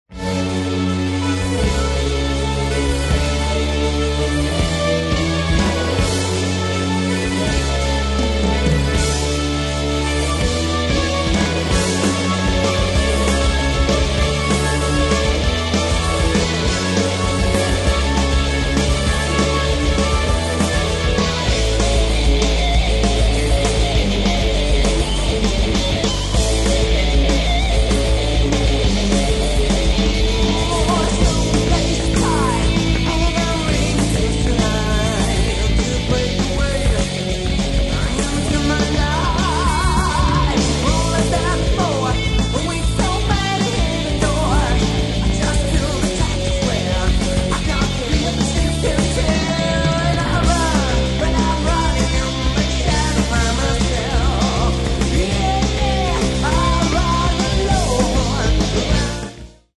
Genere : Hard Rock Prog
chitarre
basso,tastiere,voce
batteria e percussioni